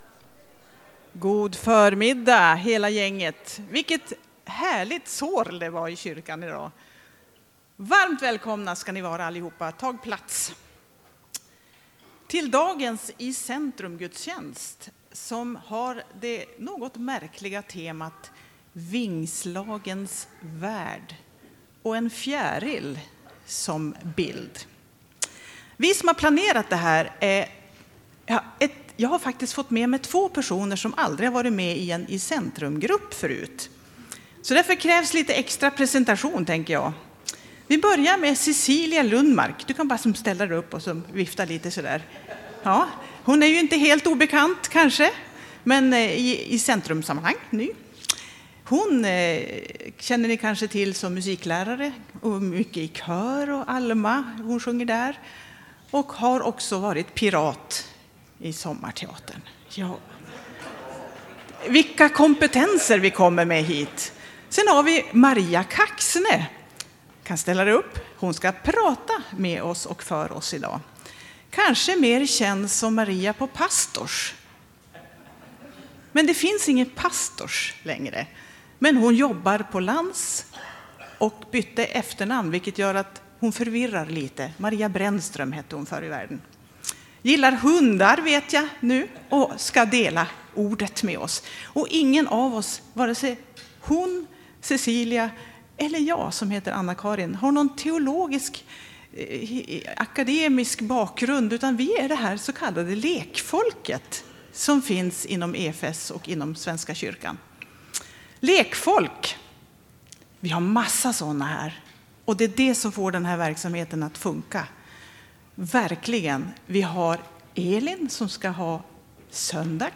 Dagens iCentrumgudstjänst hade temat ”Vingslagens värld”, en timme om handlingens konsekvenser.
Det var en välbesökt gudstjänst och vi fick flera bra saker med oss hem. En sammanfattning av budskapet kunde vara: Gud sätter värde på det vi gör i tro och av kärlek (stort som smått) och då tar Gud ansvar för skörden. Som vanligt erbjöds översättning av gudstjänsten till engelska.